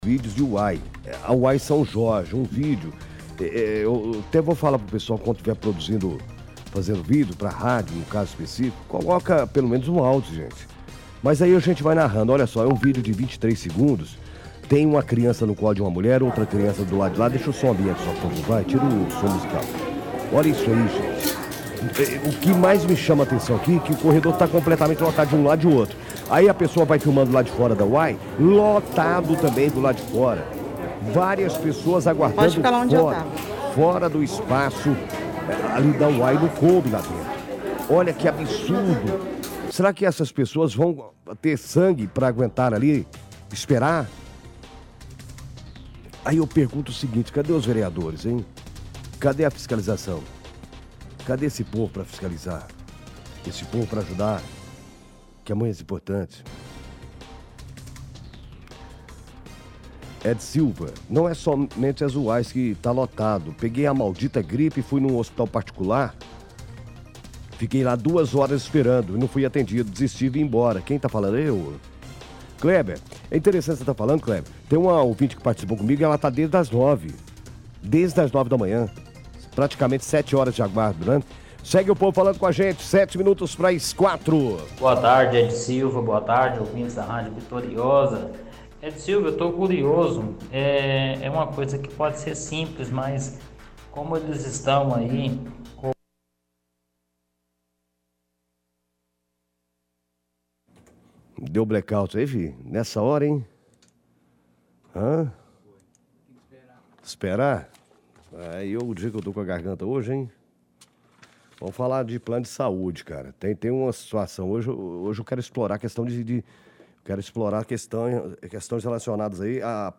Ligação Ouvintes – Gripe UAIs/Hospitais Particulares